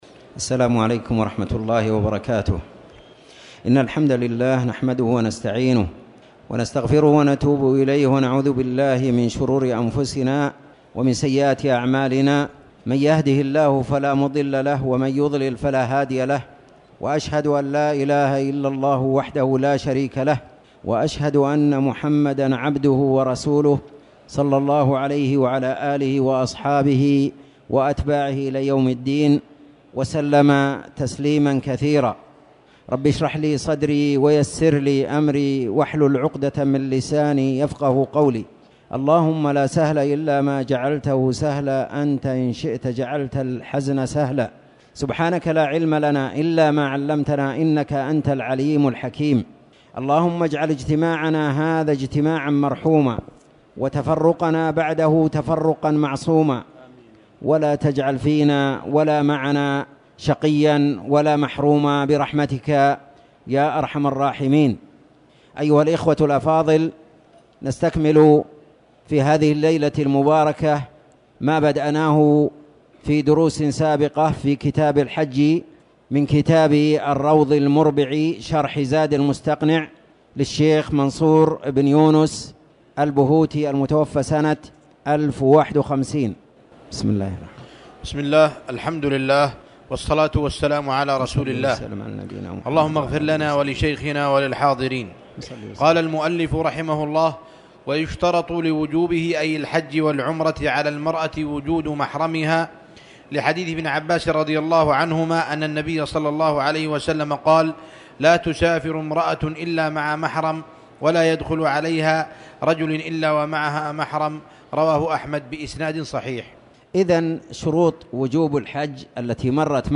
تاريخ النشر ٢١ جمادى الآخرة ١٤٣٨ هـ المكان: المسجد الحرام الشيخ